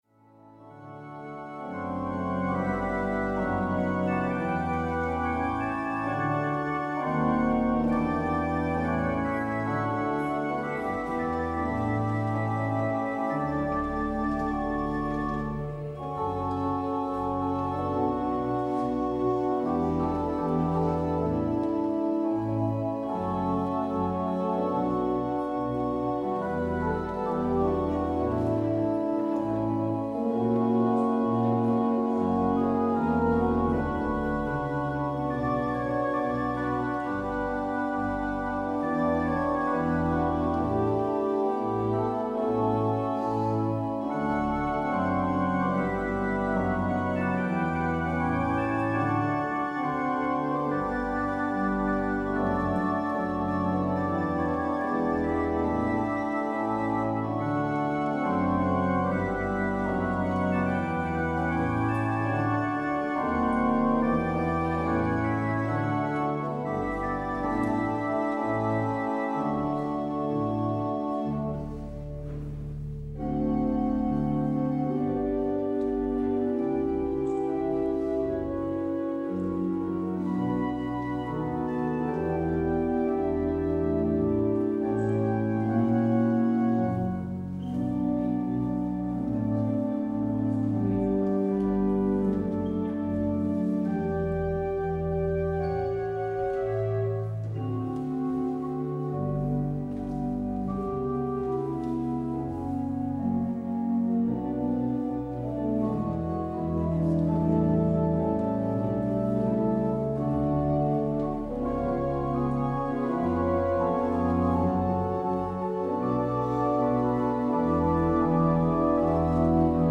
 Beluister deze kerkdienst hier: Alle-Dag-Kerk 23 april 2025 Alle-Dag-Kerk https